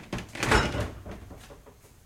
fridgeopen.ogg